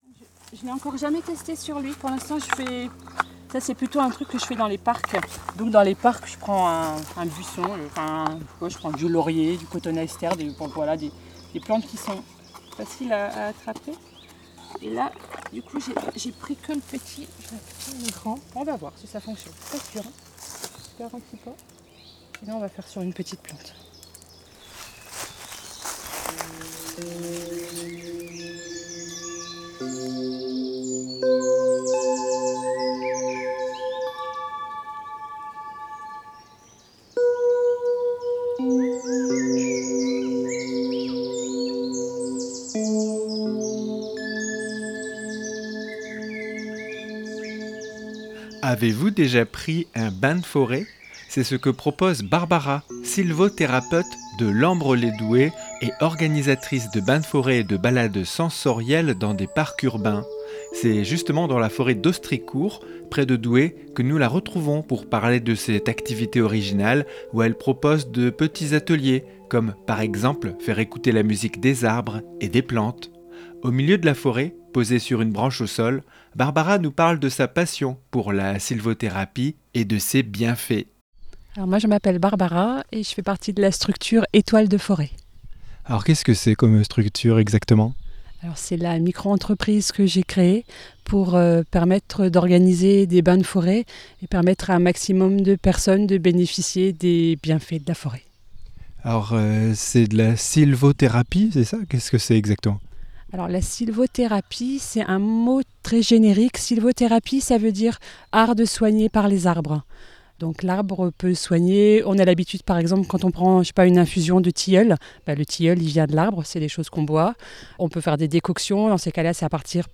C’est justement dans la forêt d’Ostricourt, près de Douai, que nous la retrouvons pour parler de cette activité originale où elle propose de petits ateliers, comme par exemple faire écouter la musique des arbres et des plantes.